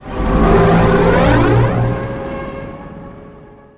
boost_1.wav